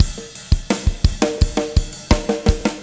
20 rhdrm85snare.wav